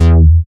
70.04 BASS.wav